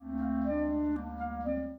Reconnaître les cris d’animaux imités par les instruments.